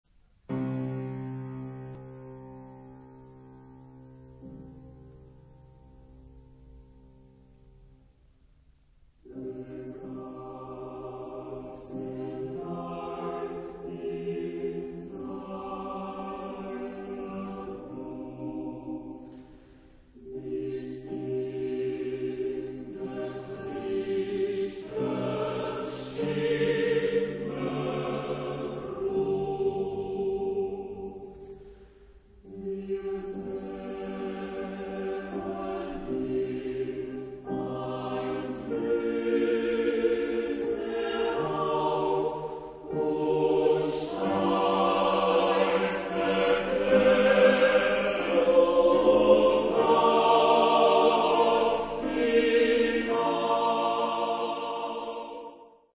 Genre-Style-Form: Romantic ; Lied ; Secular
Type of Choir: SATB  (4 mixed voices )
Instruments: Piano (1)
Tonality: C minor